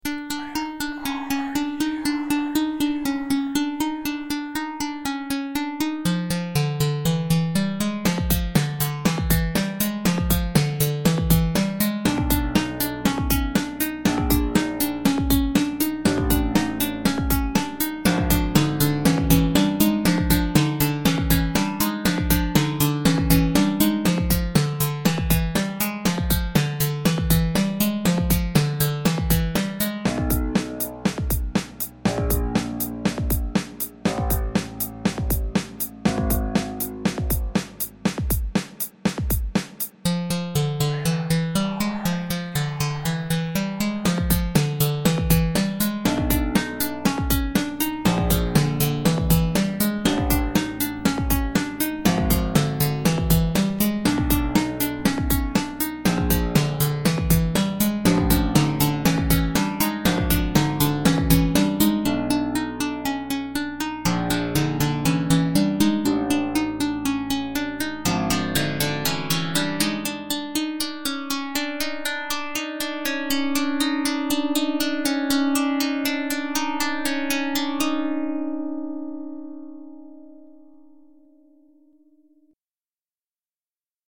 Where Are You - frantic sound and pace to this song.